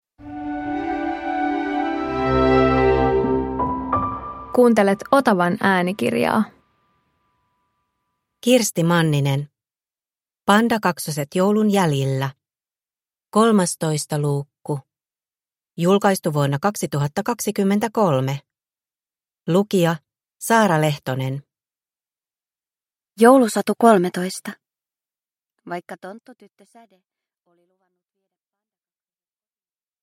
Pandakaksoset joulun jäljillä 13 – Ljudbok